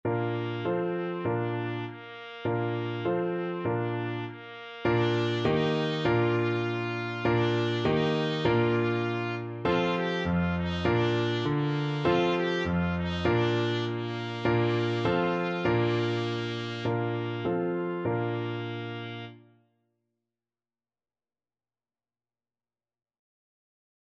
Trumpet
Tempo Marking:
4/4 (View more 4/4 Music)
Bb4-G5
Bb major (Sounding Pitch) C major (Trumpet in Bb) (View more Bb major Music for Trumpet )
frere_jac_TPT.mp3